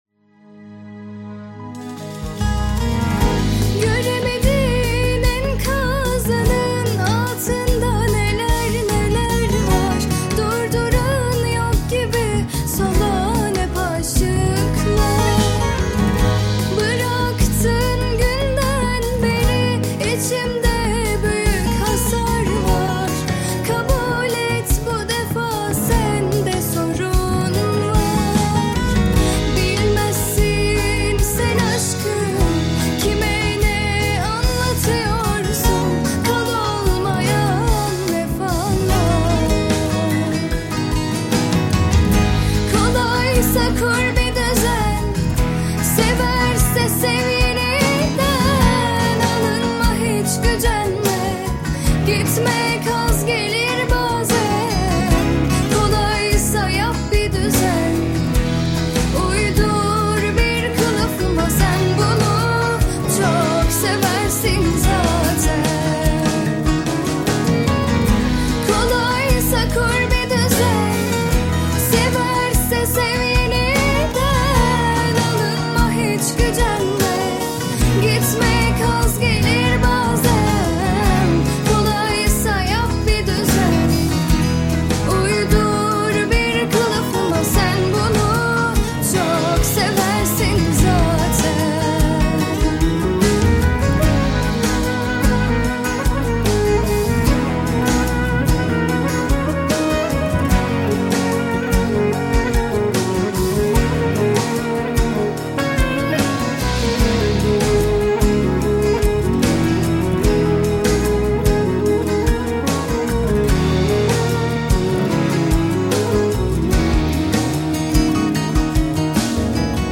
Жанр: Узбекские песни